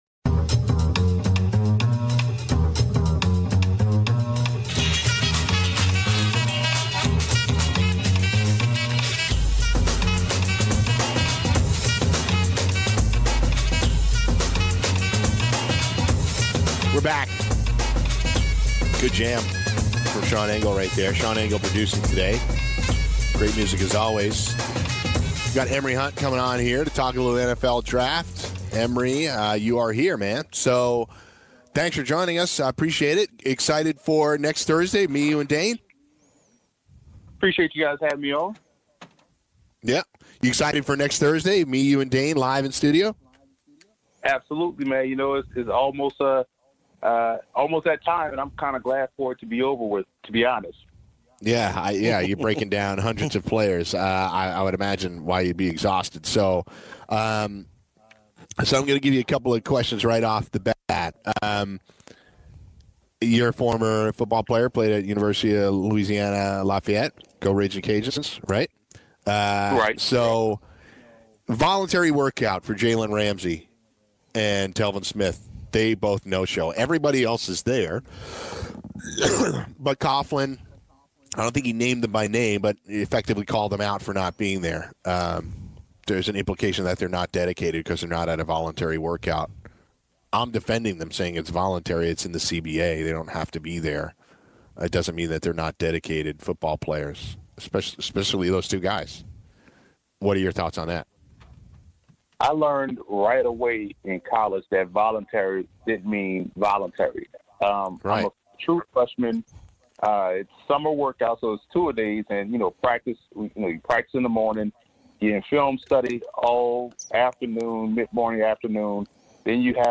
Interview: Previewing the NFL Draft